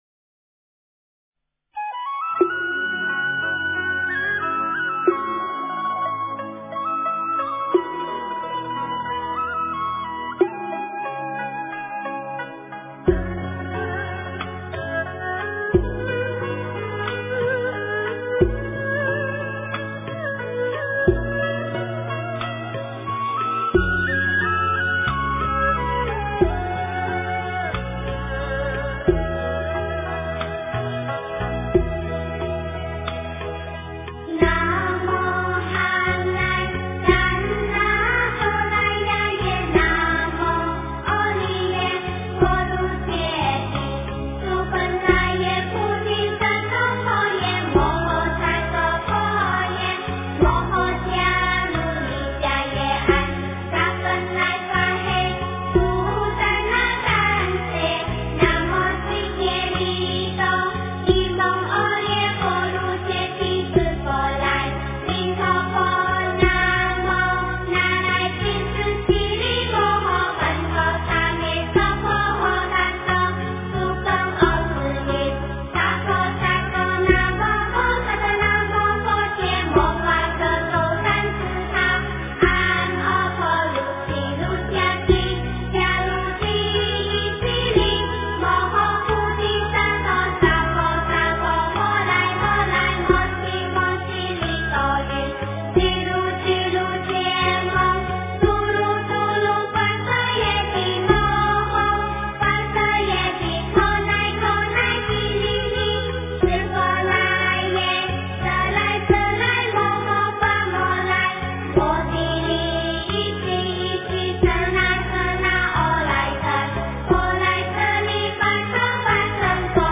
大悲咒 诵经 大悲咒--觉慧儿童合唱团 点我： 标签: 佛音 诵经 佛教音乐 返回列表 上一篇： 般若波罗蜜多心经 下一篇： 地藏经-忉利天宫神通品第一 相关文章 我佛慈悲 我佛慈悲--水琉璃...